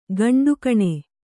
♪ gaṇḍukaṇe